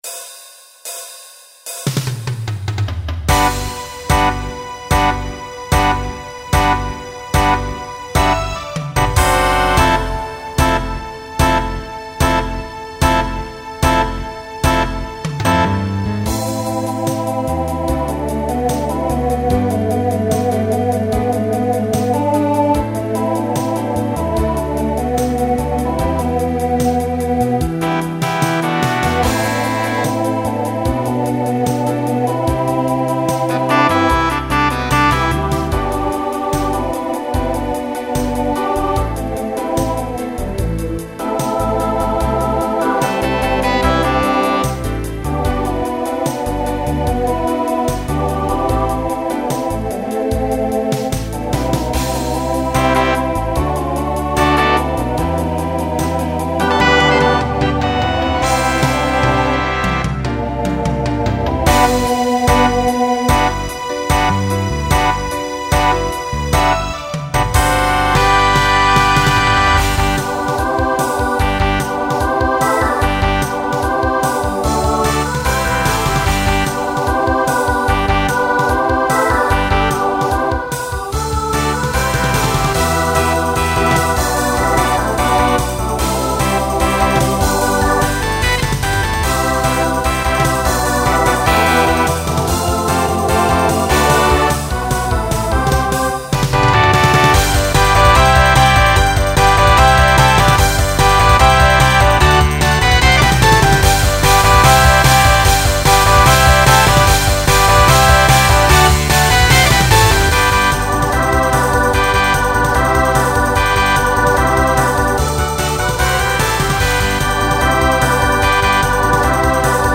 Genre Rock Instrumental combo
Voicing Mixed